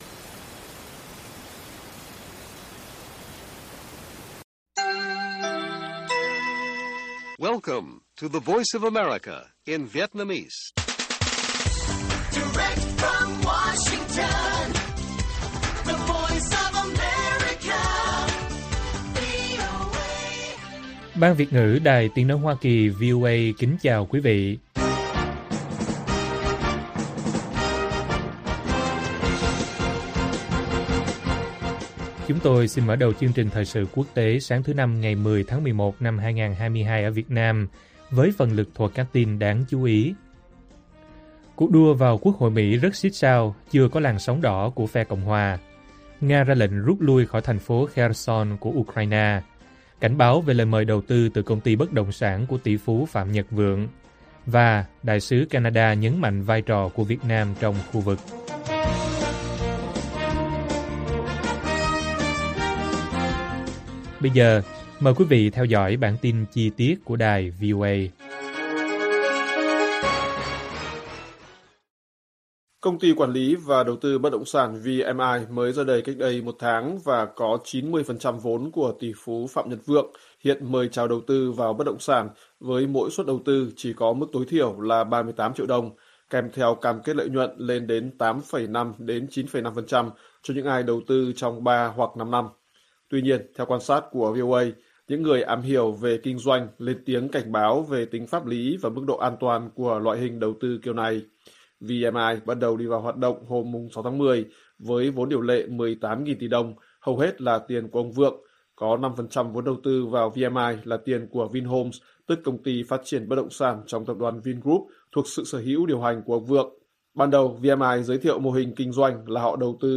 Cuộc đua vào Quốc hội Mỹ rất sít sao, chưa có ‘làn sóng đỏ’ của phe Cộng hòa - Bản tin VOA